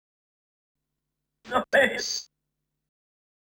Techno / Voice / VOICEFX163_TEKNO_140_X_SC2.wav
1 channel